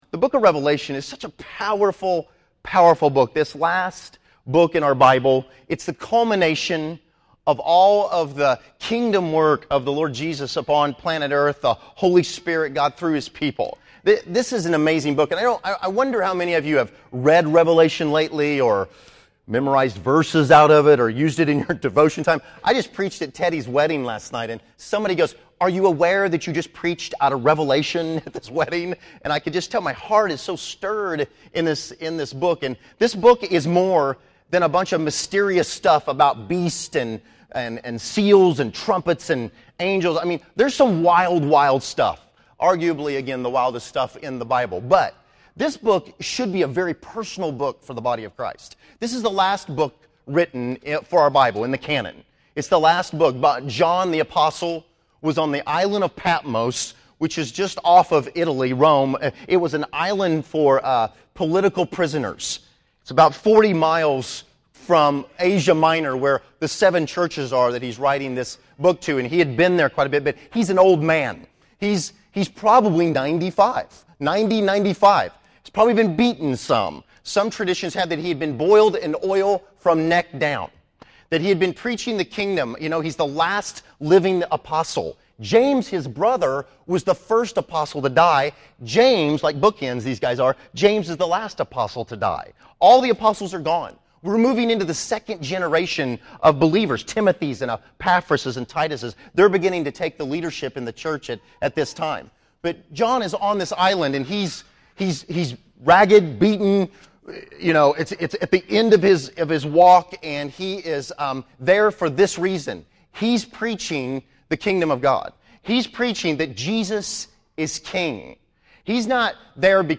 Category: Sermons | Location: El Dorado